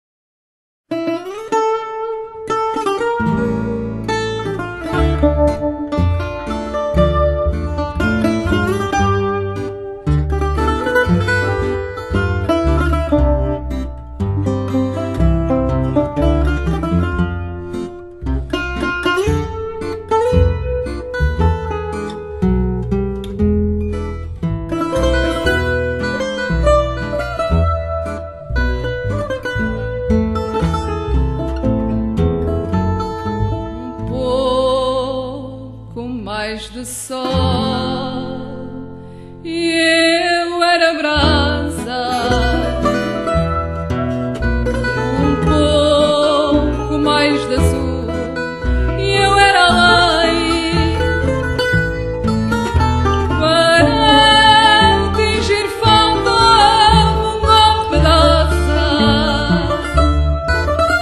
Fado
Guitare
Contrebasse